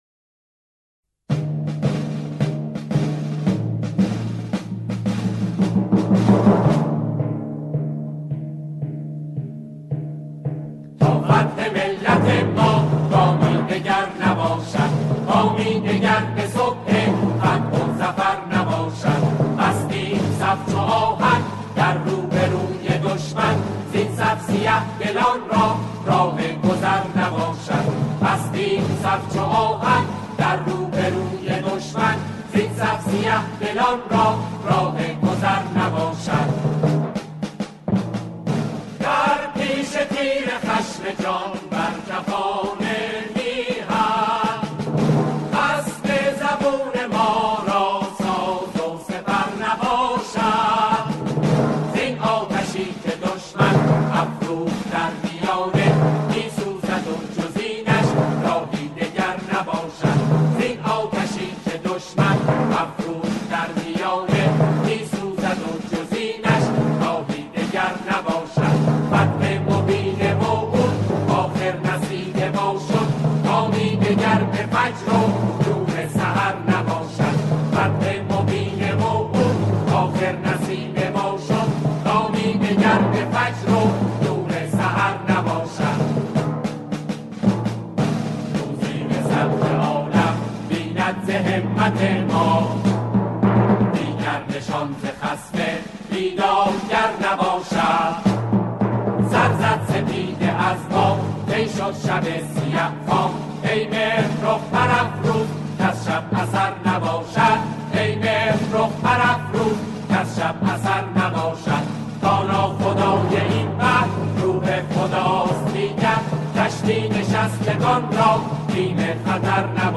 قطعه